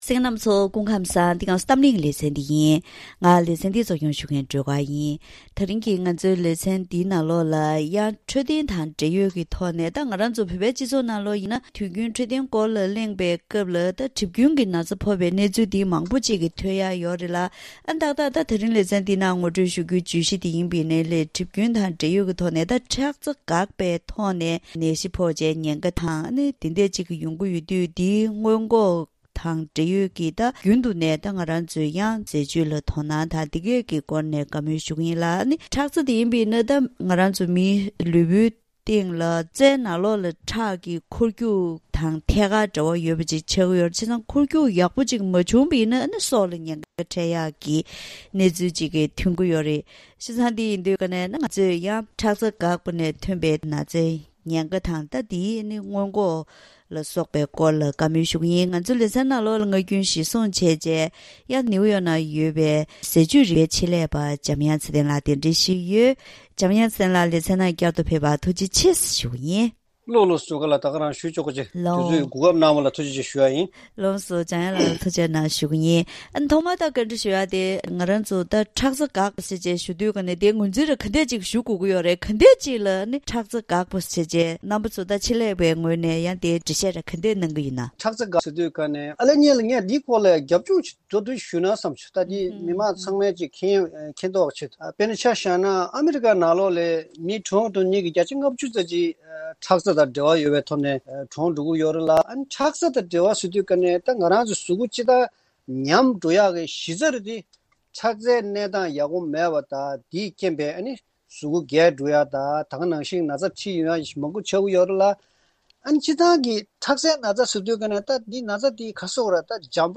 ཁྲག་རྩ་འགག་པ་ནས་ཐོན་པའི་ནད་རིགས་ཀྱི་ཉེན་ཁ་དང་འགོག་ཐབས་སྐོར་ཟས་བཅུད་རིག་པའི་ཆེད་ལས་པར་བཀའ་འདྲི་ཞུས་པ།